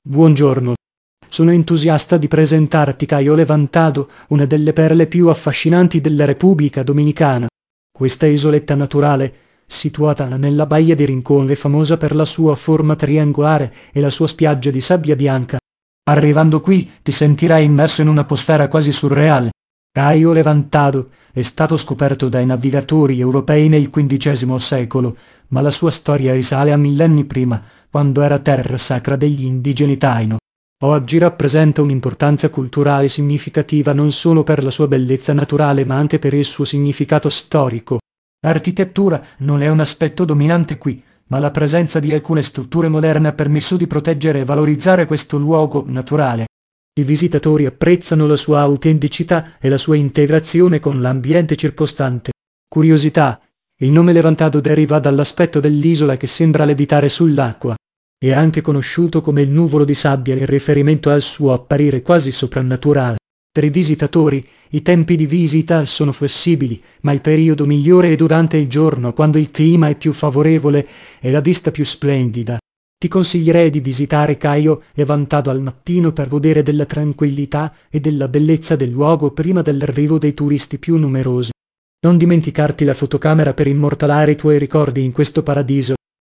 tts / cache